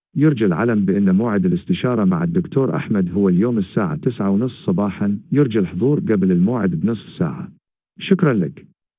male_arabic